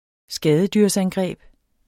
Udtale [ ˈsgæːðədyʁs- ]